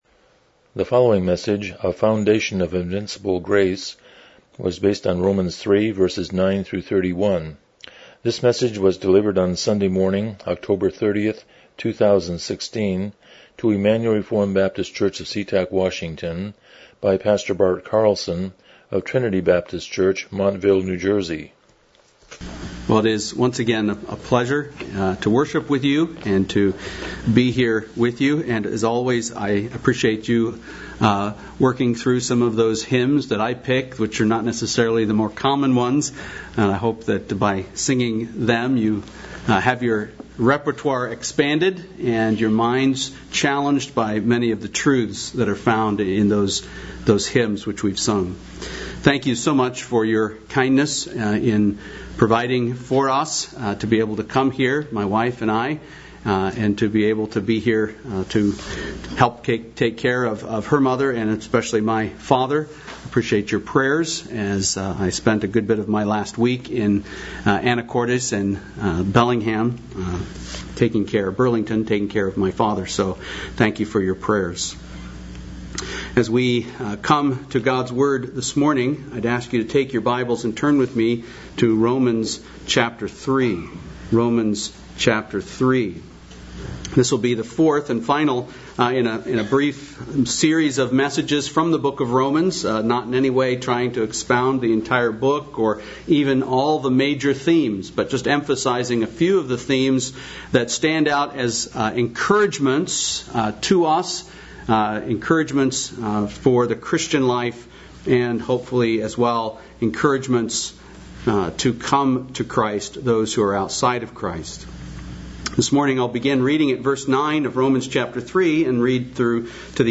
Miscellaneous Service Type: Morning Worship « Worldview